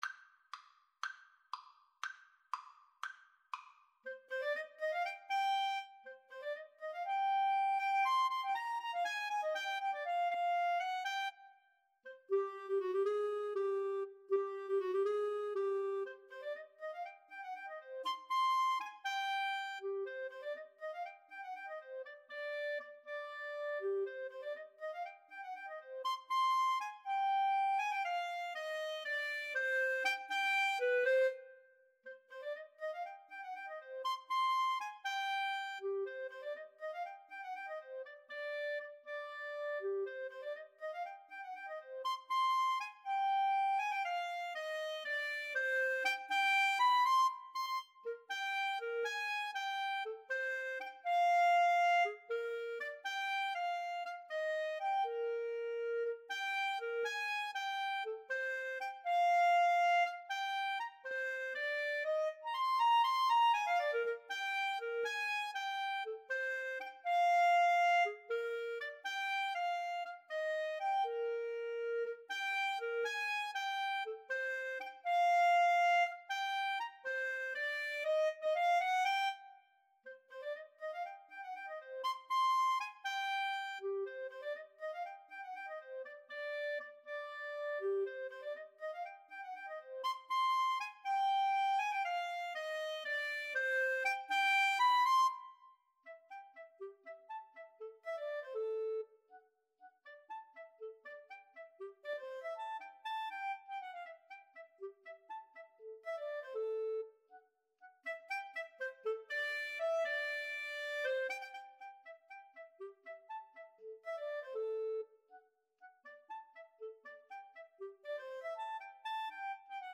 2/4 (View more 2/4 Music)
Allegretto Misterioso = 120
Clarinet Duet  (View more Intermediate Clarinet Duet Music)
Classical (View more Classical Clarinet Duet Music)